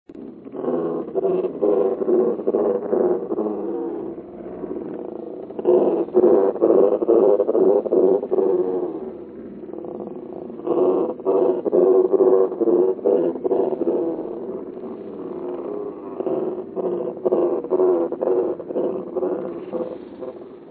The most popular this year was a stunner; I found a recording of an agitated penguin and slowed it way down.
penguin-low-and-slow.mp3